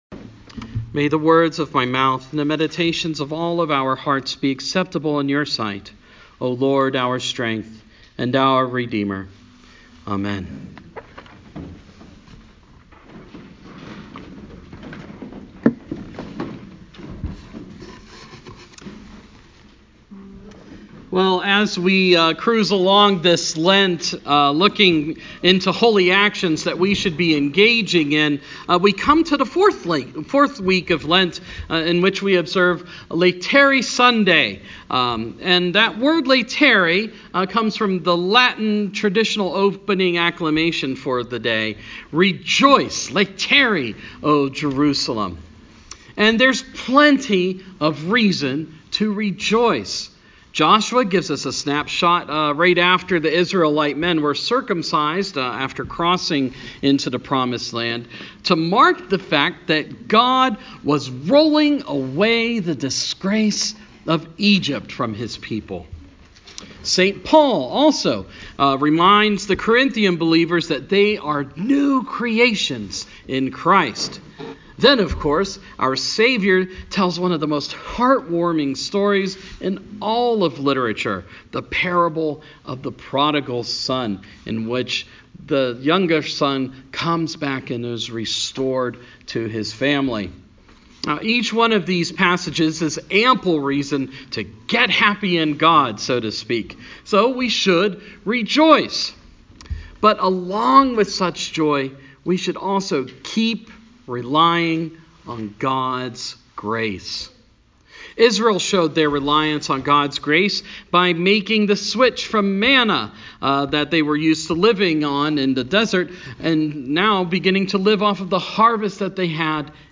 Sermon – Fourth Sunday in Lent